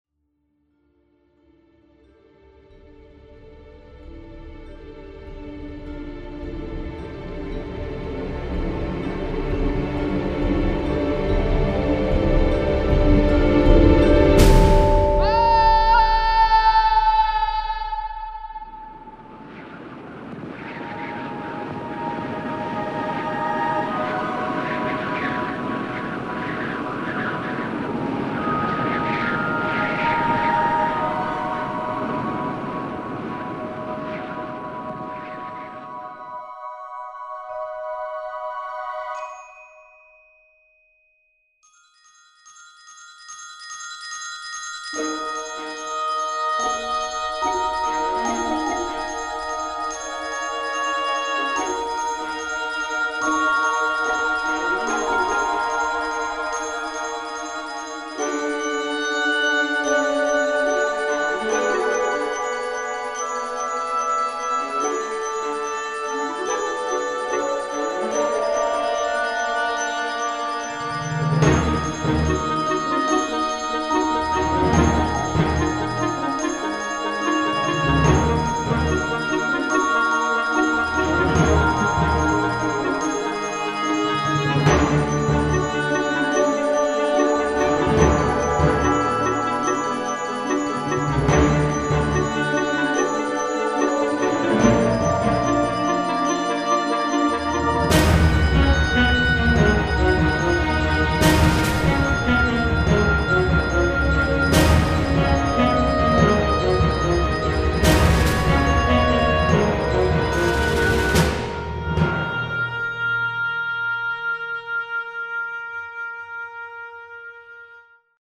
Clarté d'ensemble, harmonie équilibrée et maîtrise irréprochable des effets orchestraux
La retranscription de la légèreté est le point fort de cette compo, qui est finalement la seule à tendre résolument vers l'onirisme.